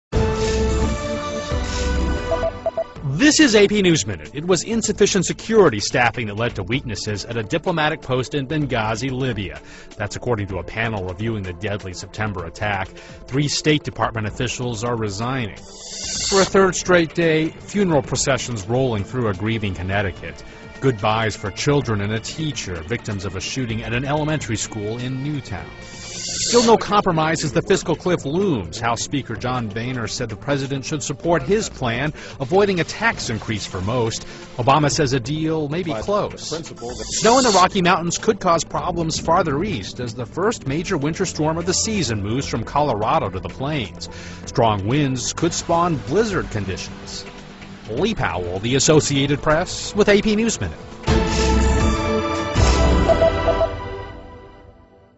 在线英语听力室美联社新闻一分钟 AP 2012-12-23的听力文件下载,美联社新闻一分钟2012,英语听力,英语新闻,英语MP3 由美联社编辑的一分钟国际电视新闻，报道每天发生的重大国际事件。